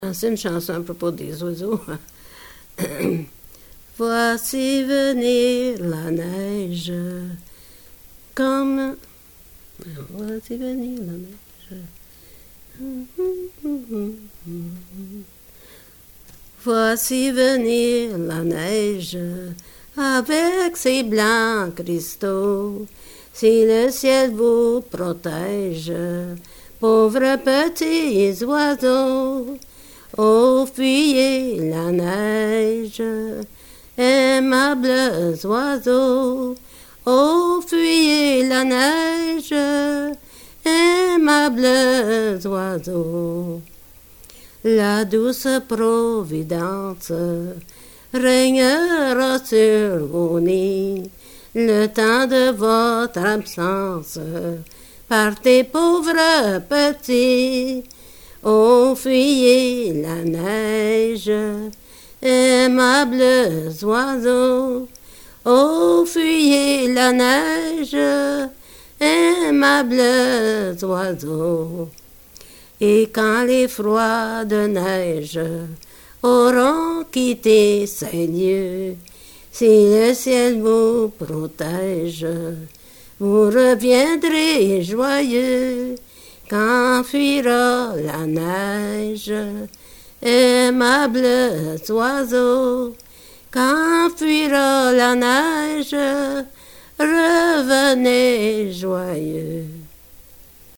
Folk Songs, French--New England
sound cassette (analog)